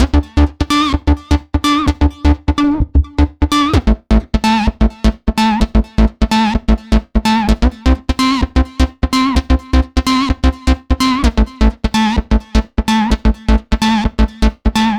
Session 11 - Lead 03.wav